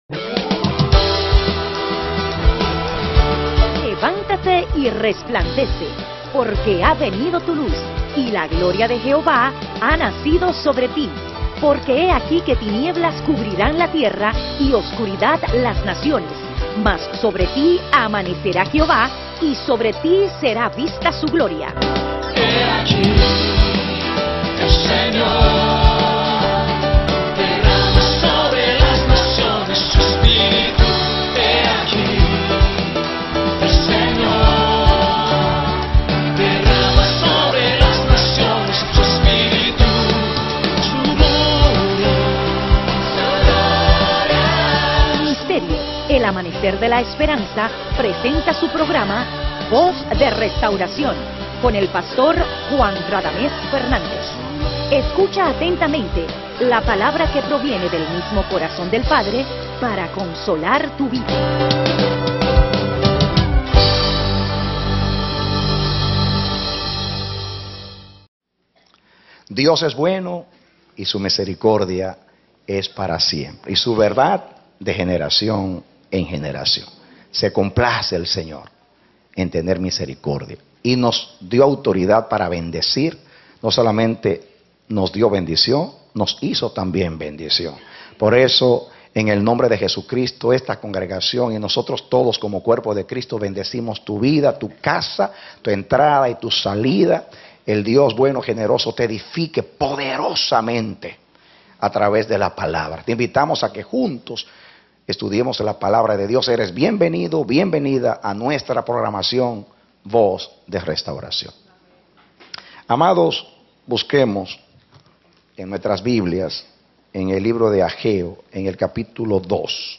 Serie de 7 Predicado Febrero 26, 2012